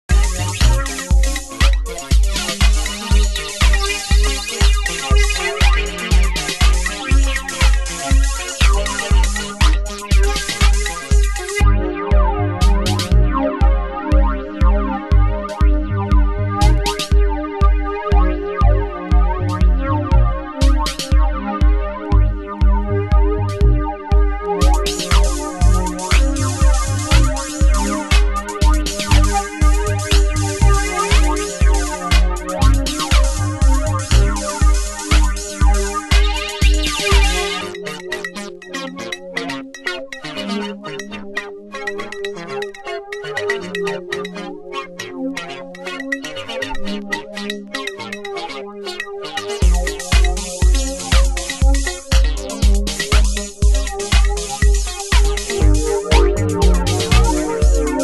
強弱のついたハイハット、アシッディーなシンセ、初期シカゴを想わせるDEEPトラック！